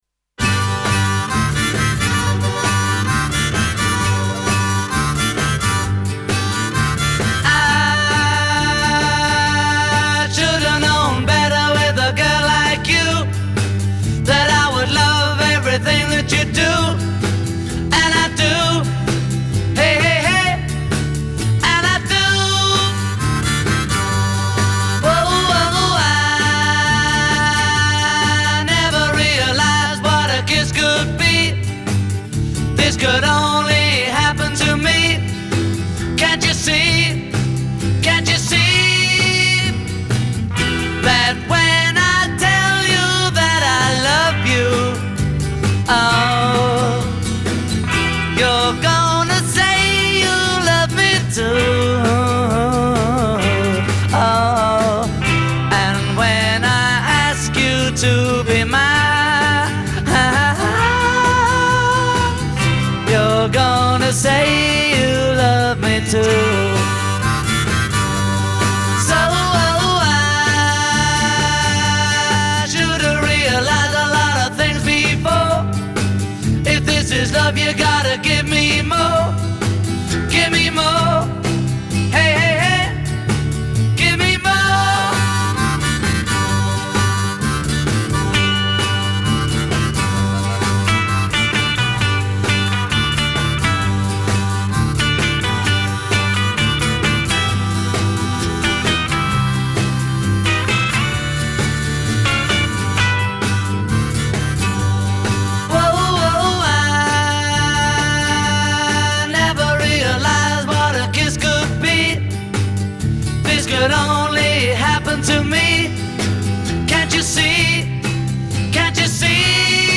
Рок-н-ролл